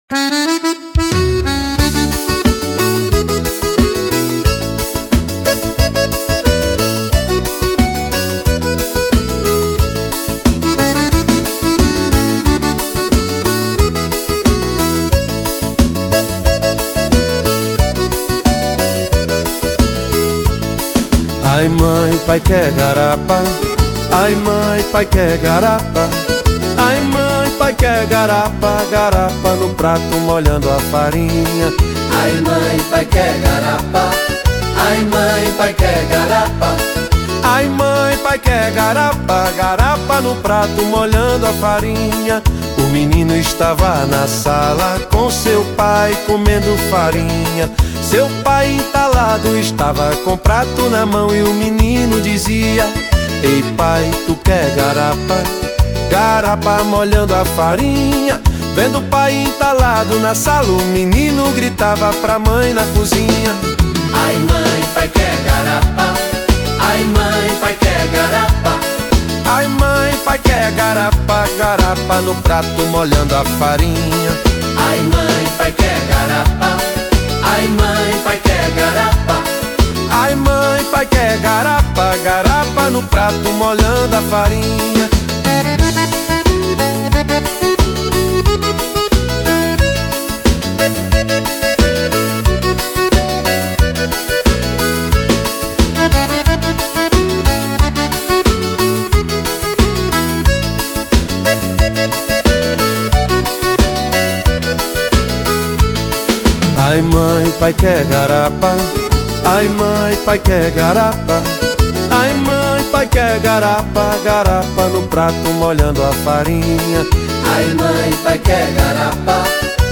EstiloRomântico